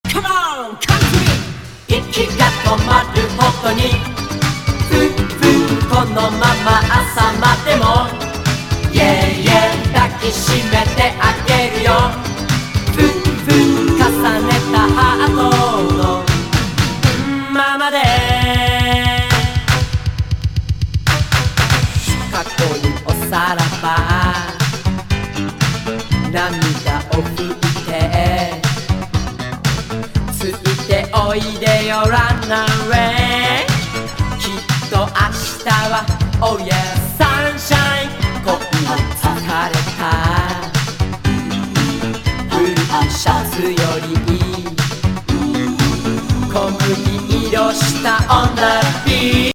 ボコーダー入りエレクトロ・モダン・ブギー歌謡!